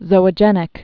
(zōə-jĕnĭk) also zo·og·e·nous (zō-ŏjə-nəs)